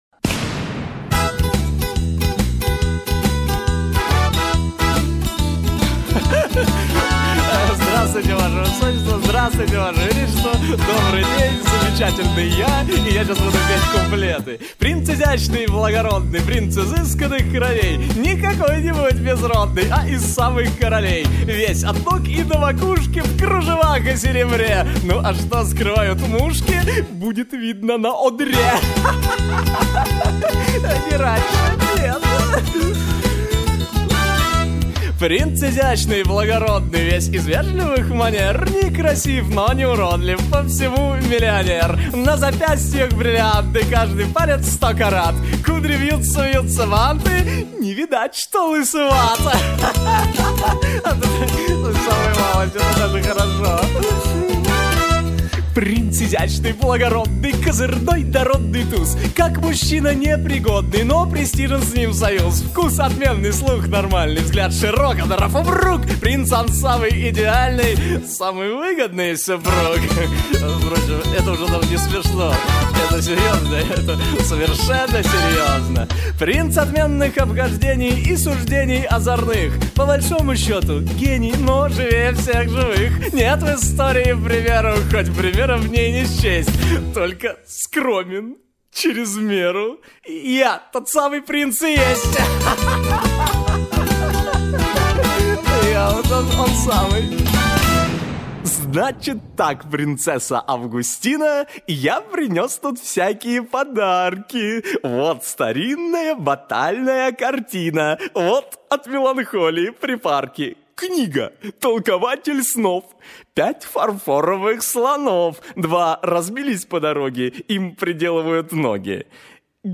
Авторская песня
Режим: Stereo